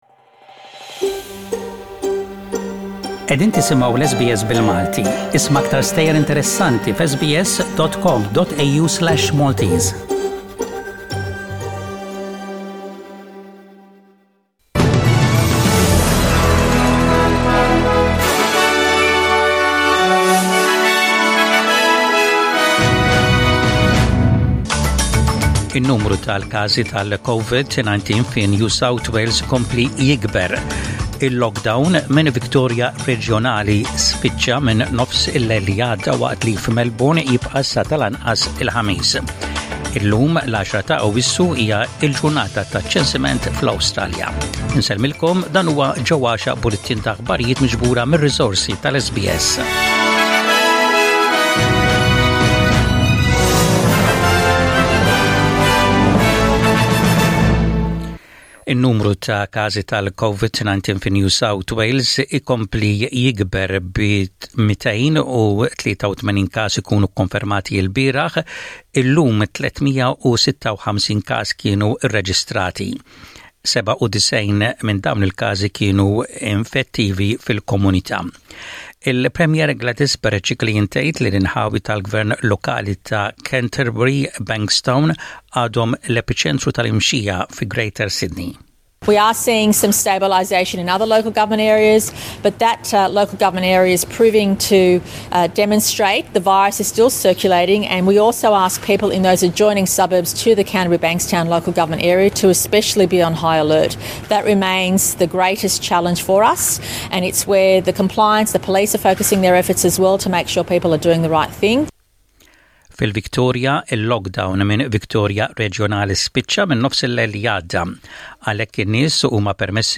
Source: Maltese News - SBS Studio